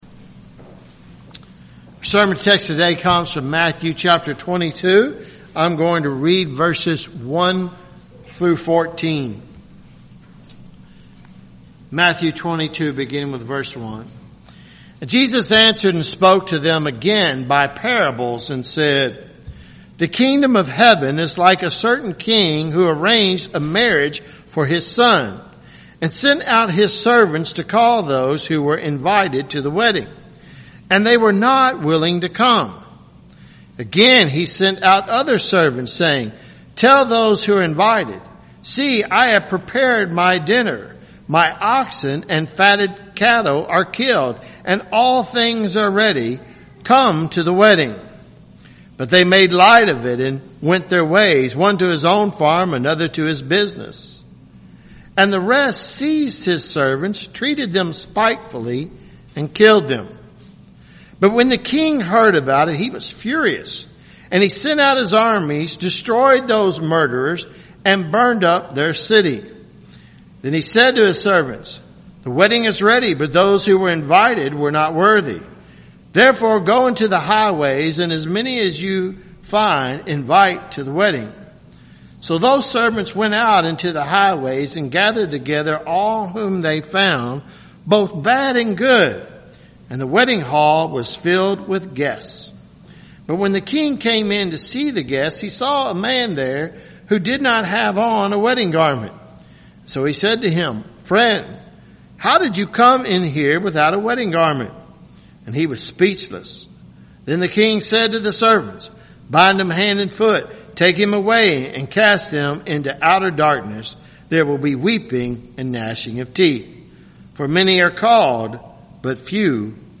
Sermon and Sunday school recordings – Christ Covenant Presbyterian Church